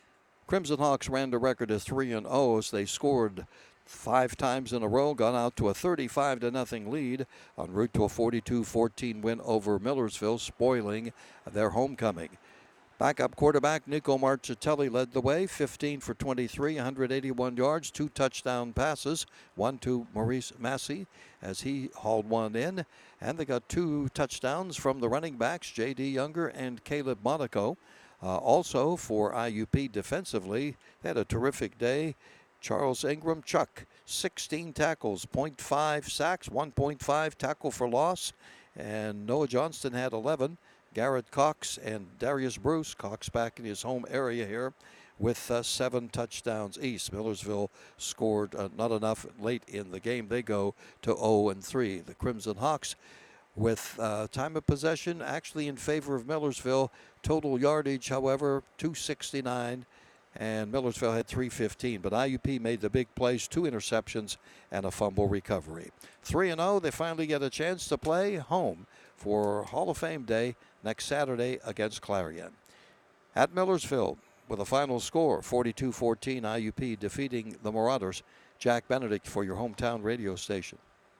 Here’s the recap